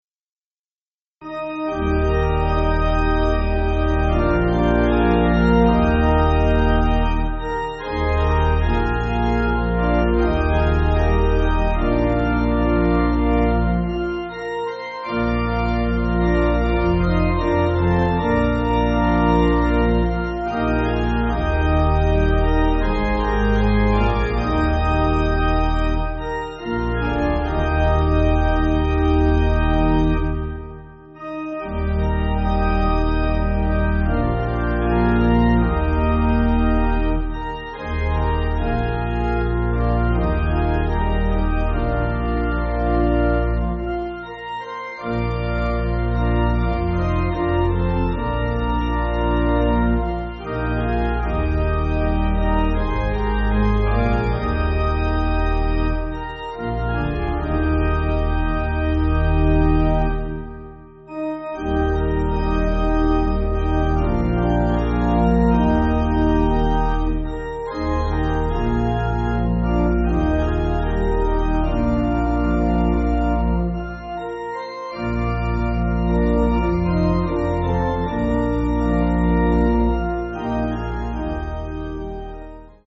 Organ
(CM)   4/Eb